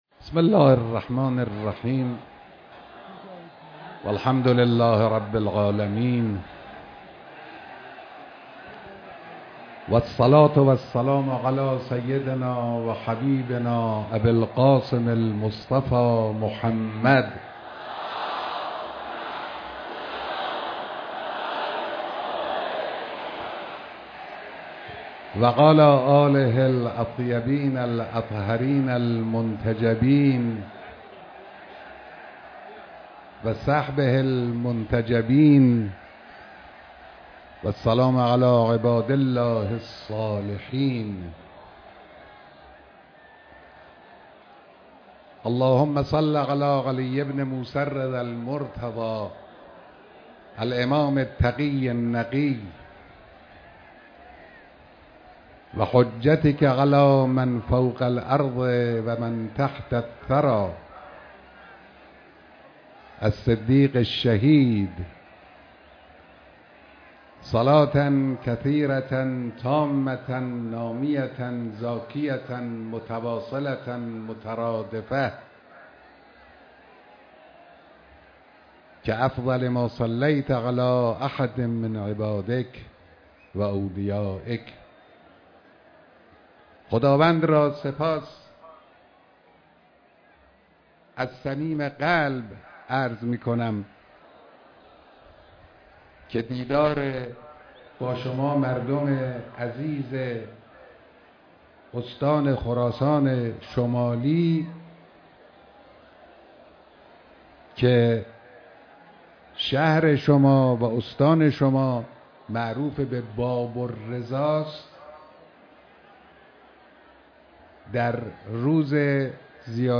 بيانات در اجتماع بزرگ مردم بجنورد